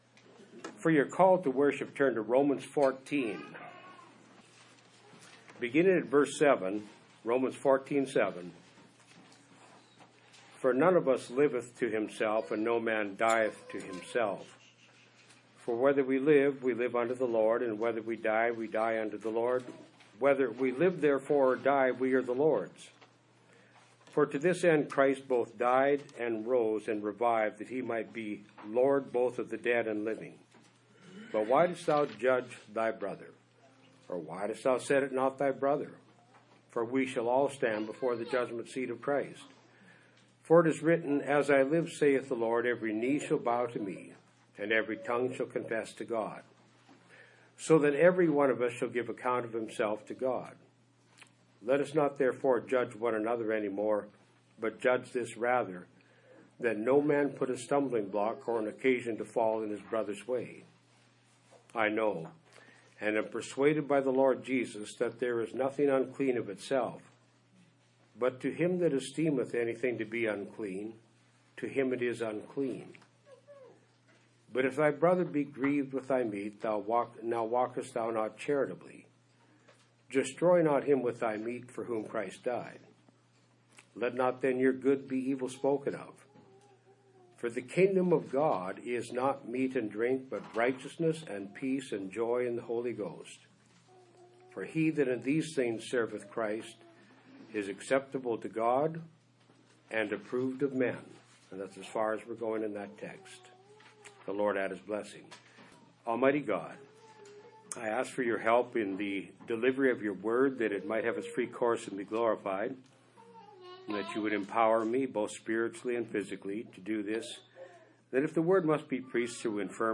Sermons by Series: 'Losing The Inheritance Of Christ's Kingdom' | Cincinnati Church